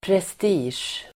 Uttal: [prest'i:sj]